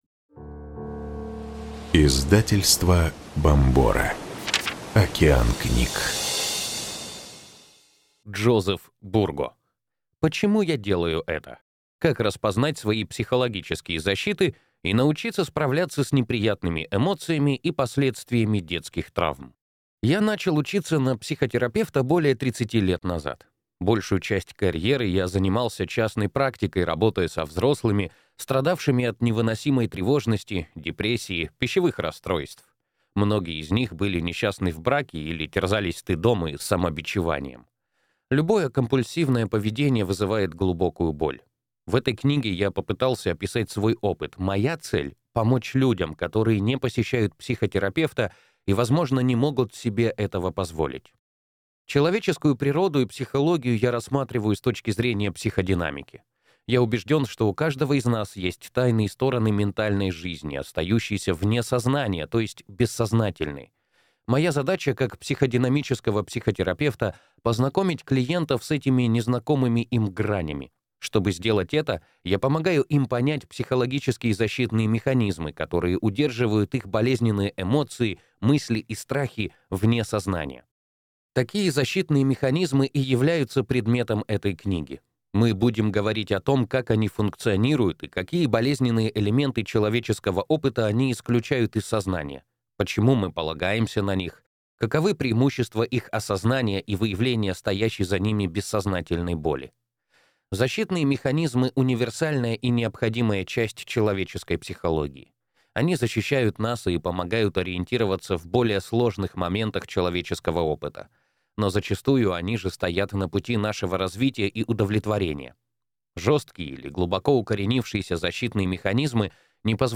Аудиокнига Почему я делаю это. Как распознать свои психологические защиты и научиться справляться с неприятными эмоциями и последствиями детских травм | Библиотека аудиокниг